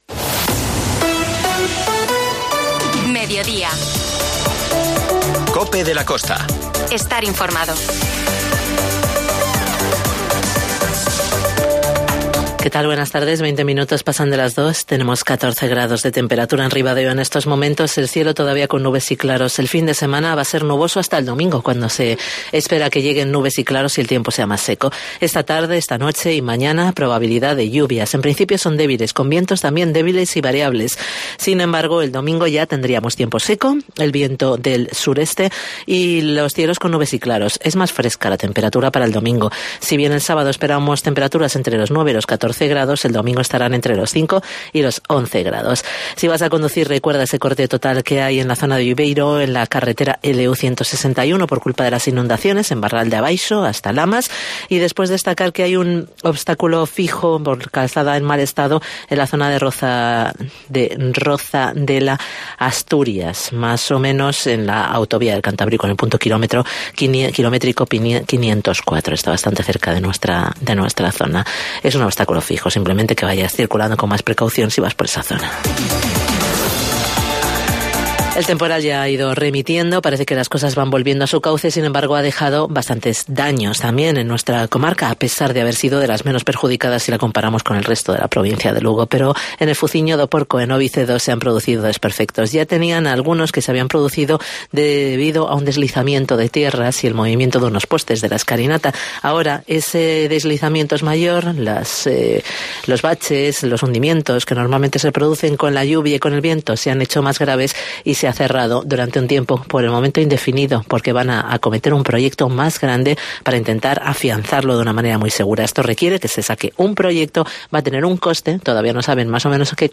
COPE de la Costa - Ribadeo - Foz INFORMATIVO